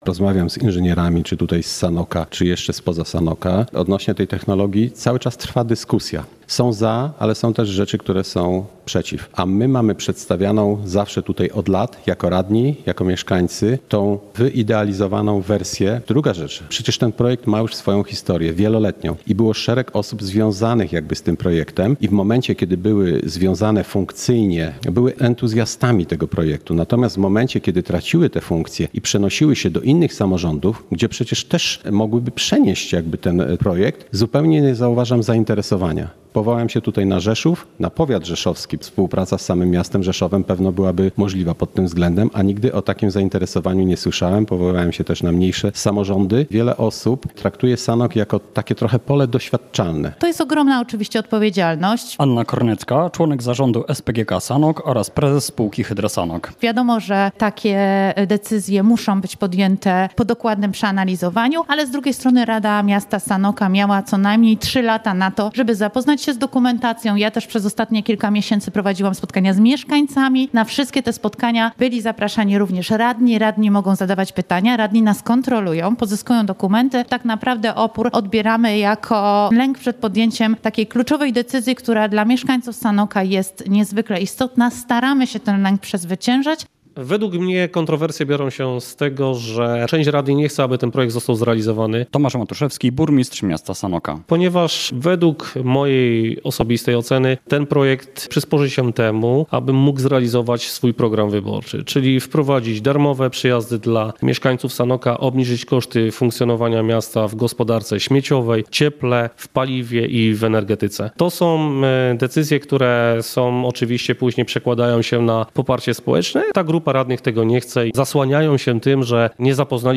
Spór o instalację do produkcji energii • Relacje reporterskie • Polskie Radio Rzeszów
Relacje reporterskie • Sanoccy radni odrzucili projekt uchwały dotyczący dzierżawy miejskich gruntów dla spółki Hydro Sanok. Decyzję podjęto podczas 48. nadzwyczajnej sesji Rady Miasta Sanoka.